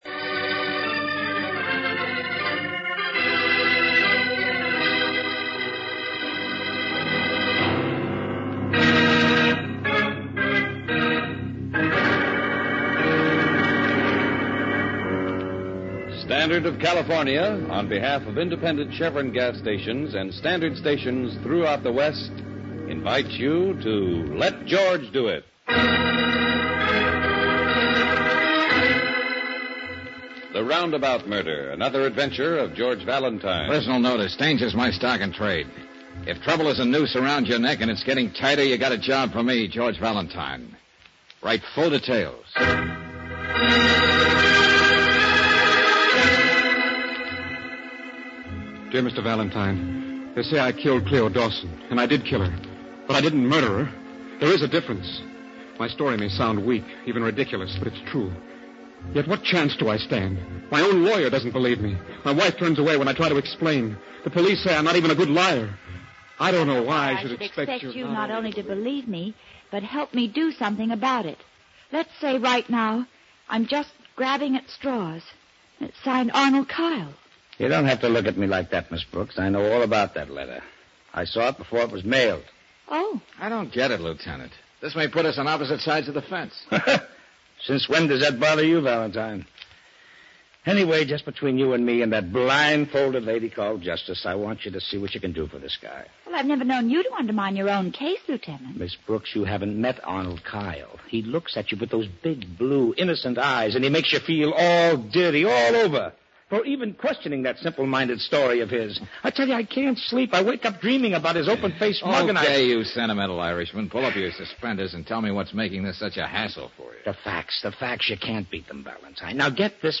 starring Bob Bailey
Let George Do It Radio Program